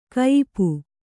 ♪ kayipu